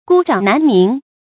注音：ㄍㄨ ㄓㄤˇ ㄣㄢˊ ㄇㄧㄥˊ
孤掌難鳴的讀法